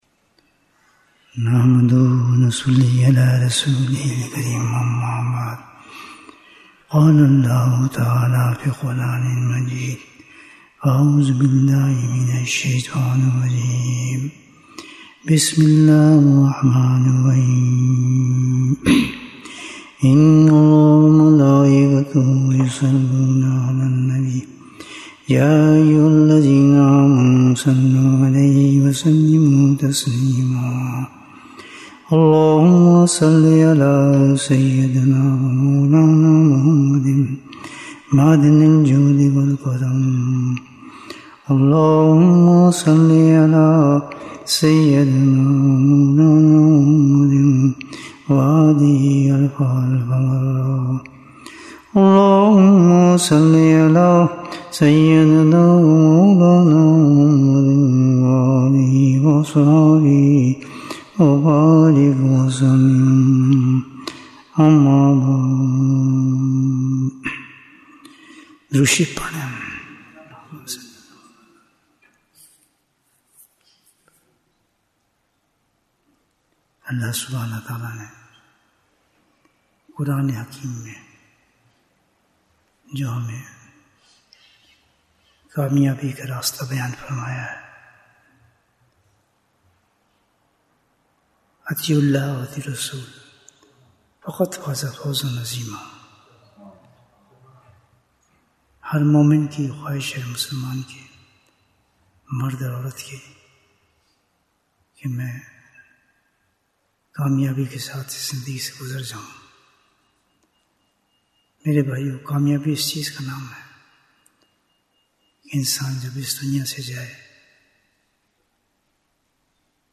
Bayan, 47 minutes10th July, 2025